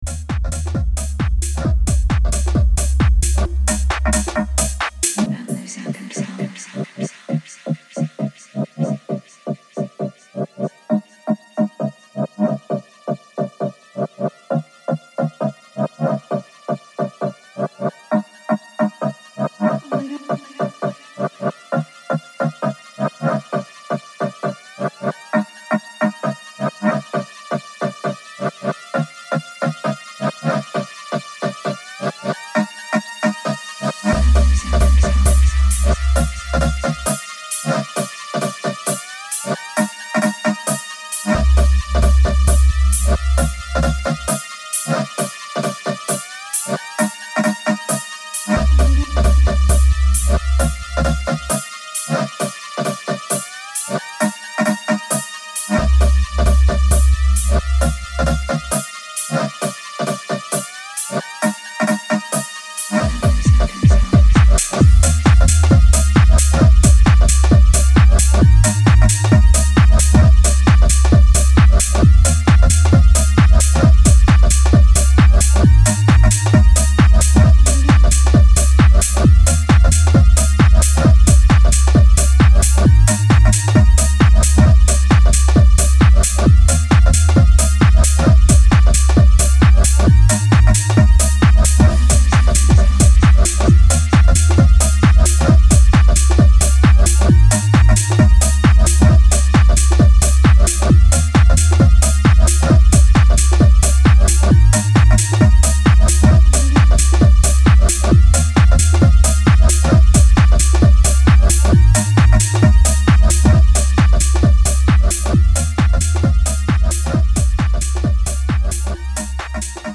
house techno & garage